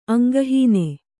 ♪ aŋgahīne